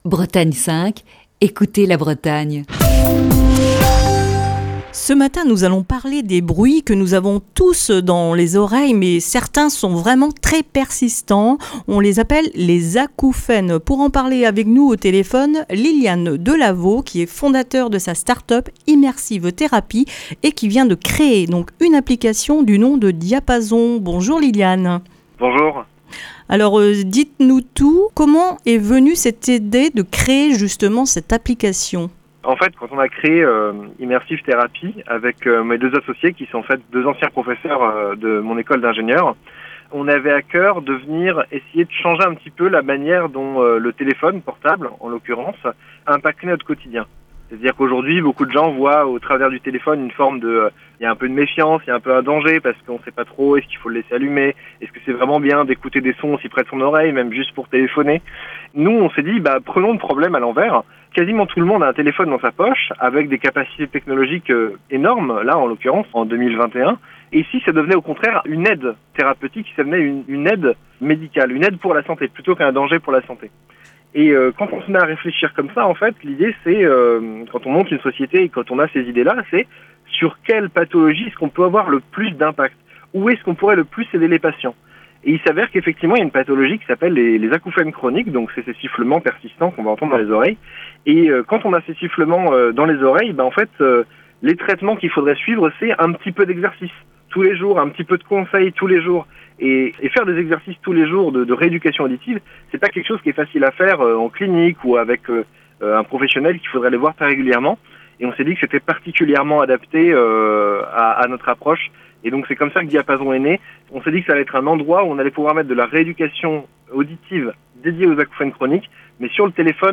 Dans le coup de fil du matin de ce mardi